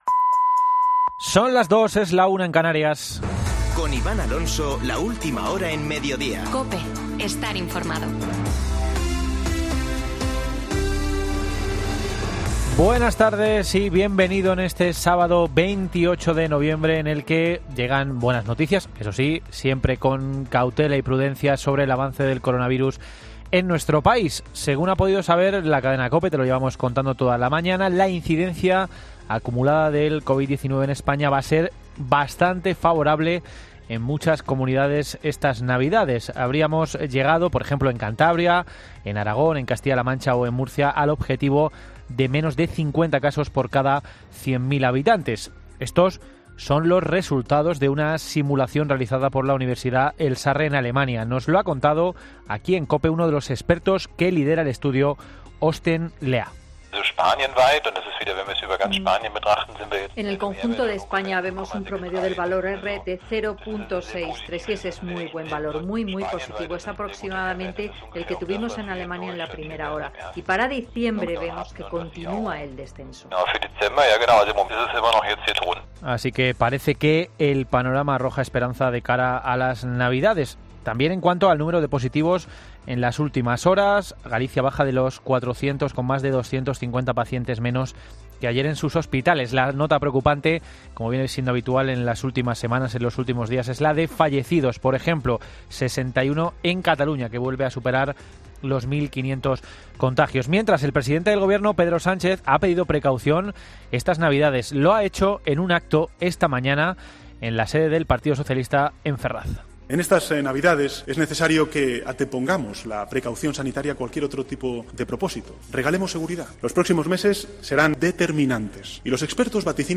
Boletín de noticias de COPE del 28 de noviembre de 2020 a las 14.00 horas